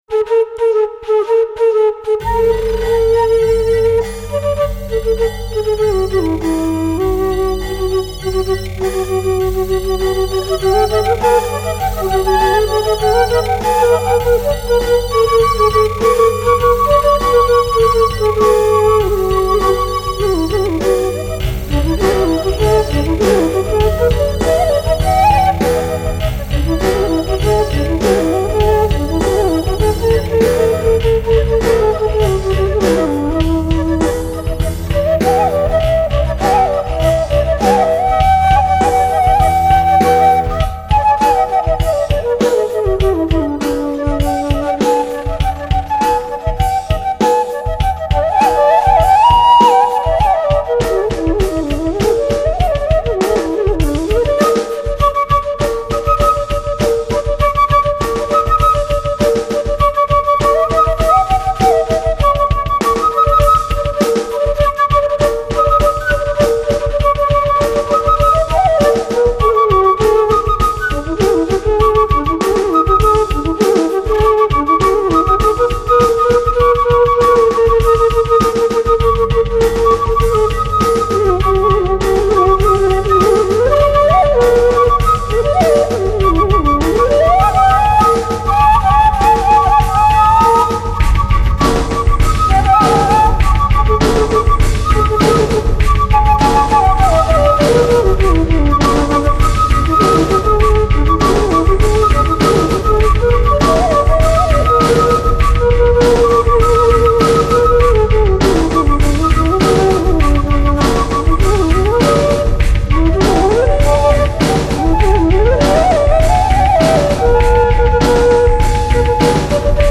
Experimental Rock flauti traversi